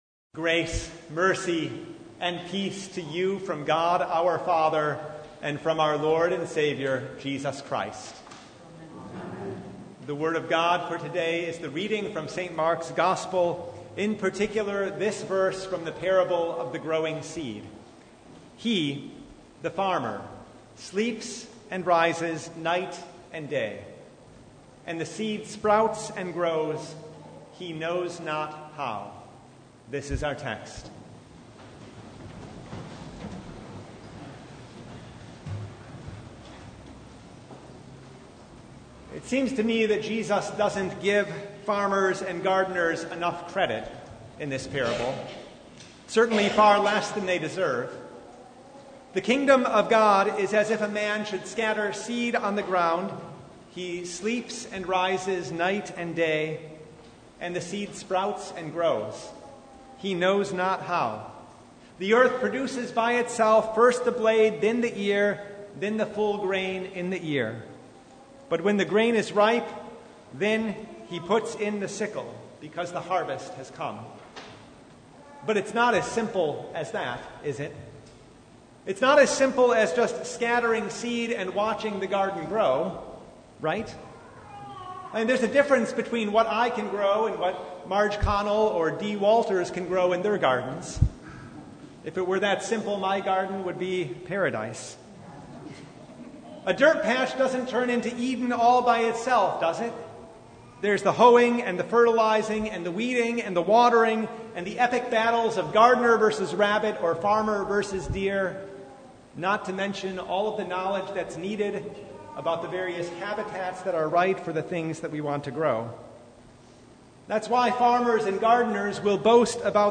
Mark 4:26-34 Service Type: Sunday The seed grows